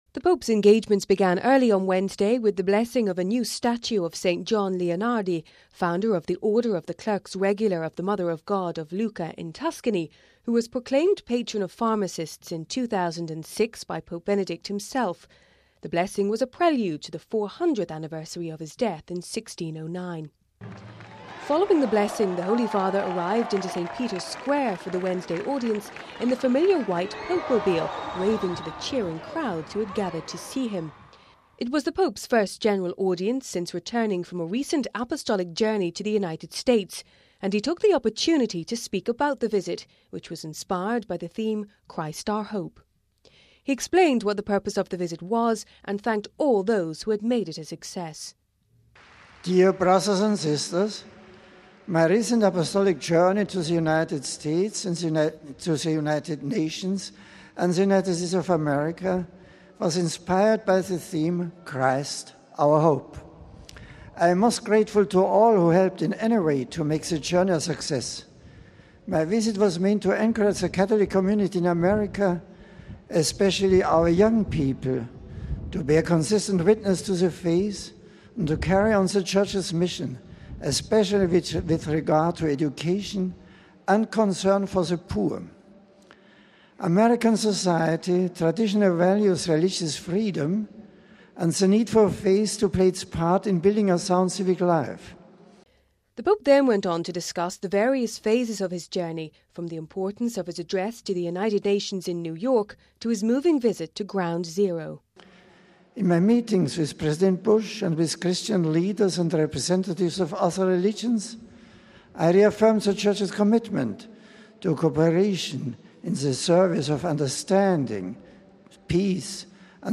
Following the blessing the Holy Father arrived into St Peter’s Square for the Wednesday audience in the familiar white pope mobile waving to the cheering crowds who had gathered to see him.